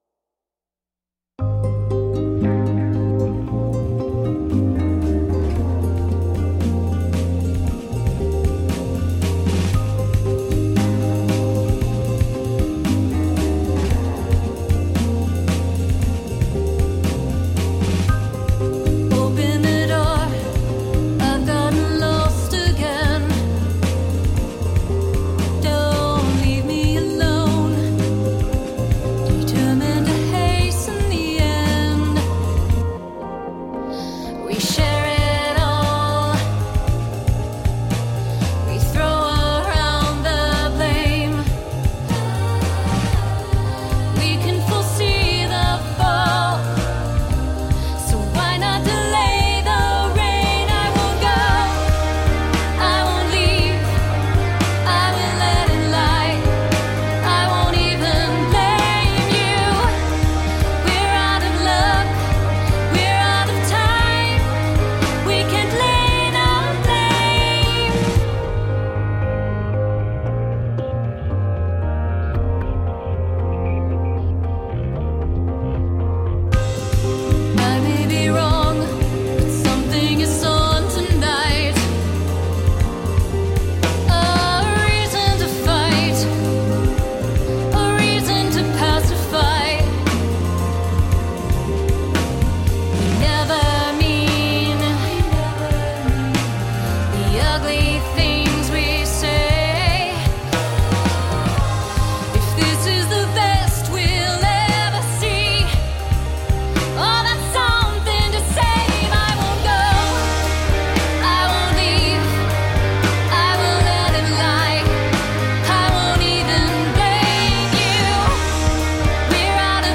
Moody blend of electronica and rock.
Tagged as: Electro Rock, Rock